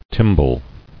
[tym·bal]